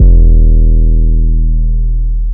DDW2 808 3.wav